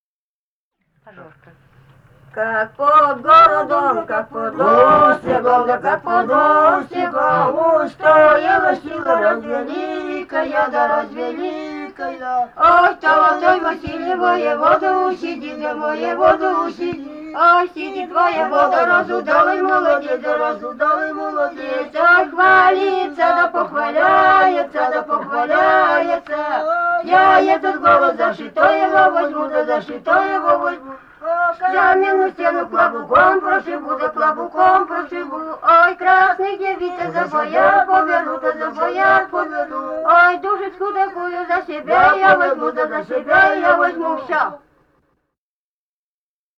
Пермский край, д. Кокуй Кунгурского района, 1968 г. И1080-10